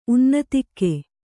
♪ unnatikke